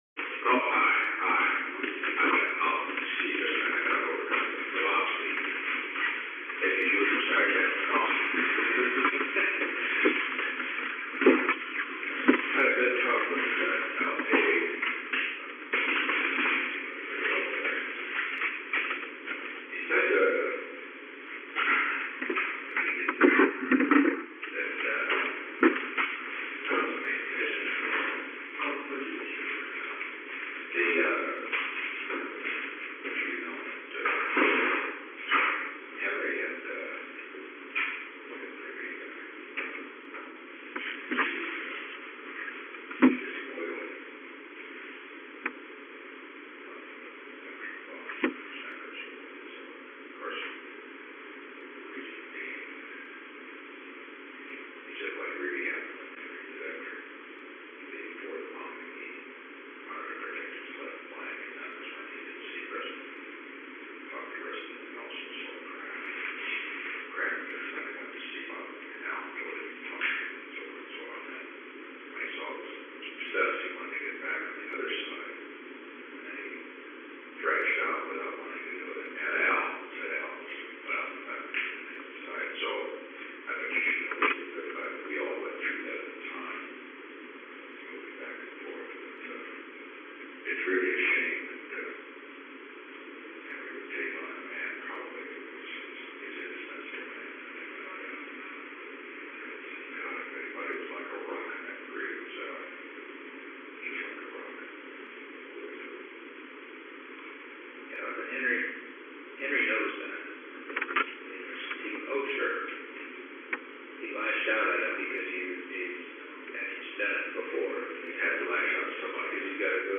Location: Oval Office The President met with H. R. (“Bob”) Haldeman.
Conversation with Alexander M. Haig, Jr. -Henry A. Kissinger An unknown man entered at 12:47 pm.
Secret White House Tapes